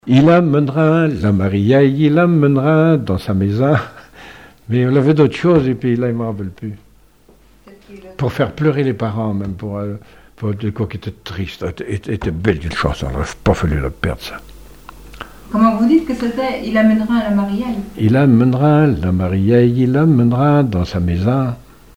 gestuel : à marcher
regroupement de chanteurs locaux
Pièce musicale inédite